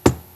klepnuti-plast.wav